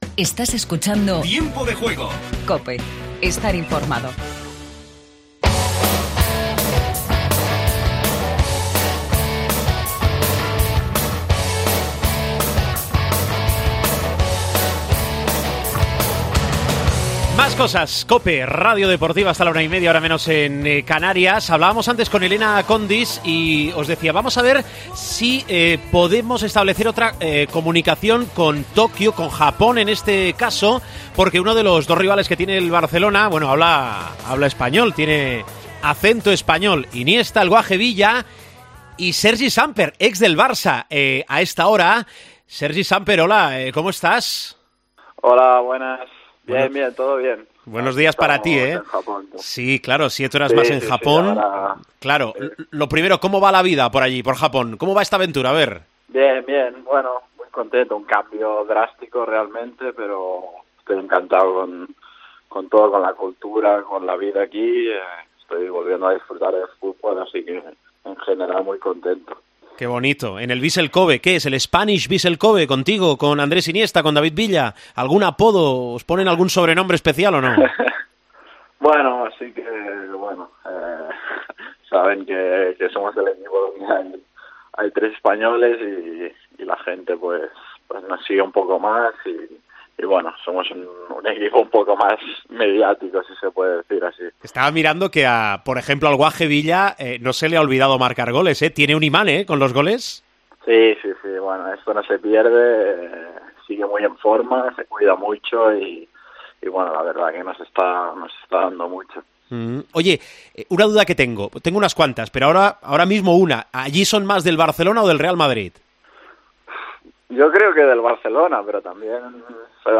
AUDIO: Entrevista Sergi Samper. Repasamos Mundial Natación y el British Open de golf.